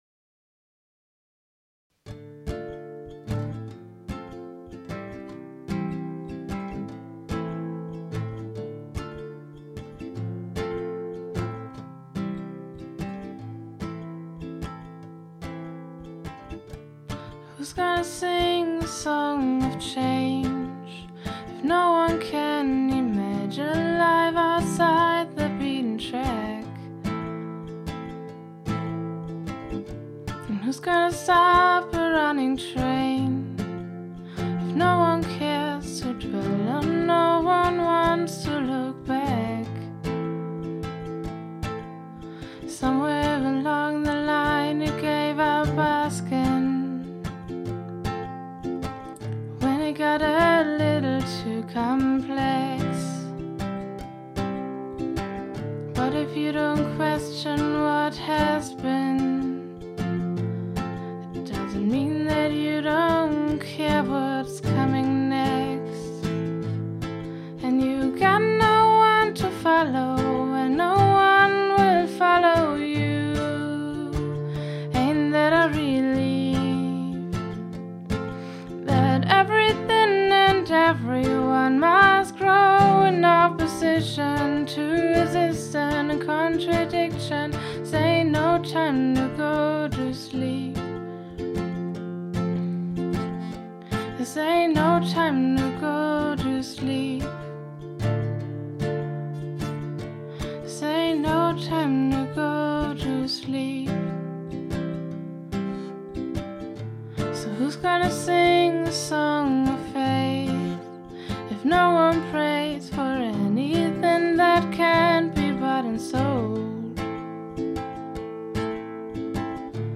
gemeinsamen Recording-Session